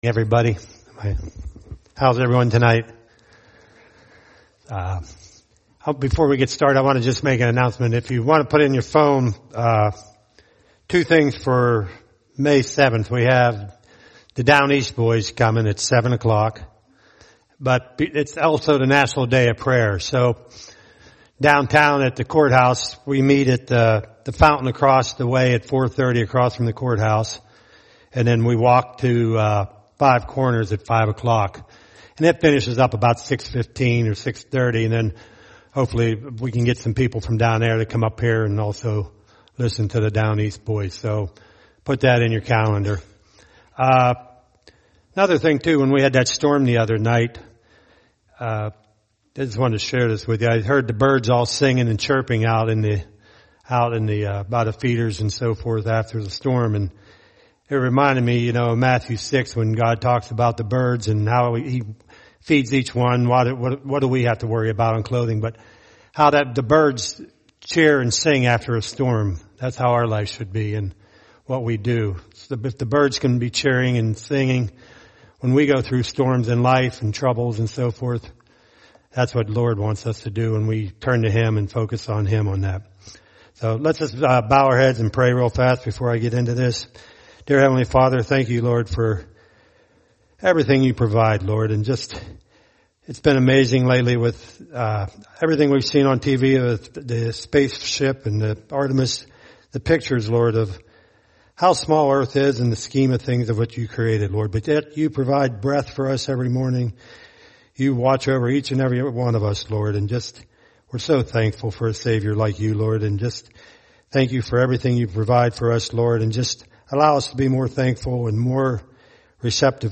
From Topics: "Guest Speakers"